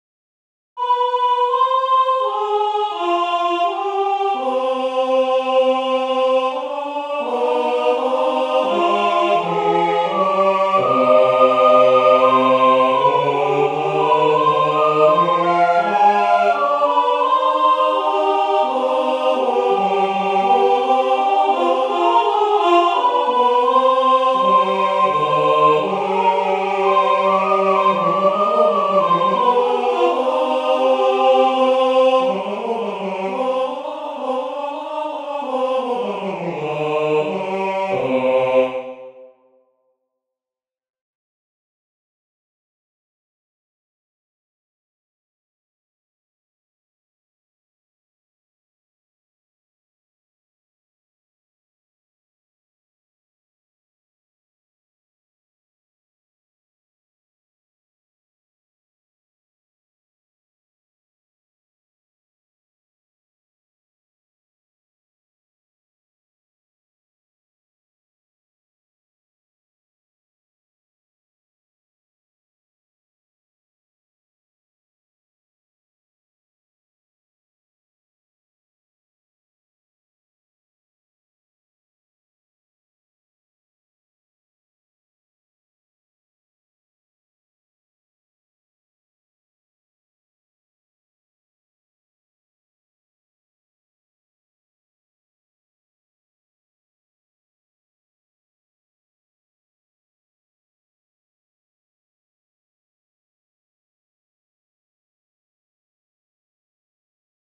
Incomplete 2 voice canon, want feedback on my counterpoint